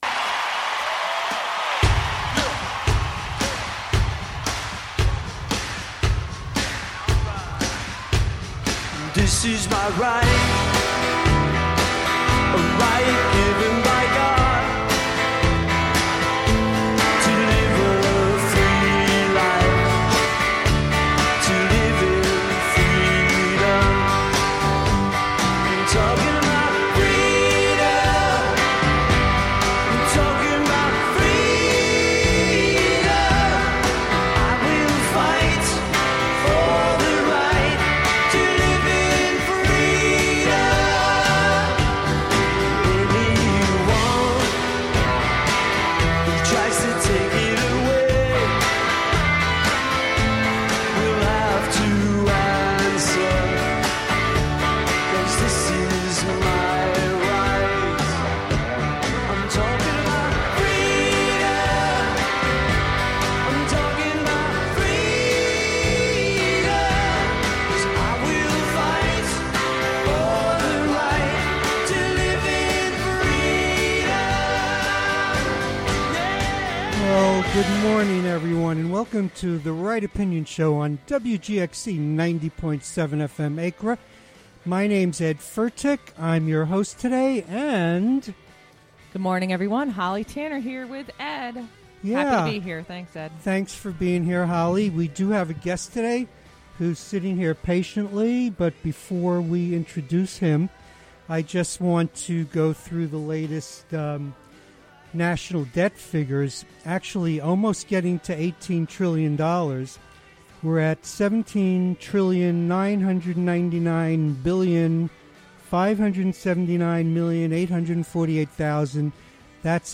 Patrick Grattan, Chairman of the Columbia County Board of Supervisors, will discuss a variety of issues, including the county's plan for the Pine Haven Nursing and Rehabilitation Center, the Columbia County Airport and more. Columbia County Clerk Holly Tanner will participate in the conversation, as well.